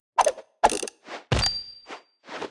Media:Sfx_Anim_Classic_Colt.wavMedia:Sfx_Anim_Super_Colt.wavMedia:Sfx_Anim_Ultra_Colt.wavMedia:Sfx_Anim_Ultimate_Colt.wav 动作音效 anim 在广场点击初级、经典、高手、顶尖和终极形态或者查看其技能时触发动作的音效
Sfx_Anim_Baby_Colt.wav